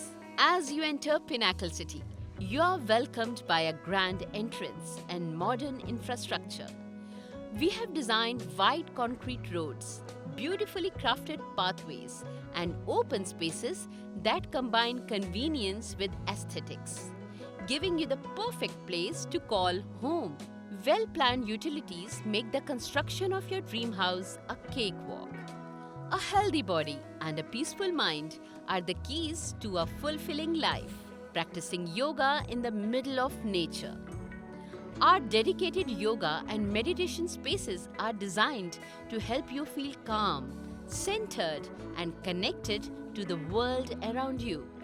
Browse professional voiceover demos.
Institutional, inspirational, compelling, educated, smooth, formal....